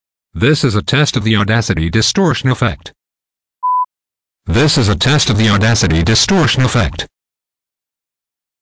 The Distortion effect does exactly what it was designed to do.
This is an example of the type of thing that it does: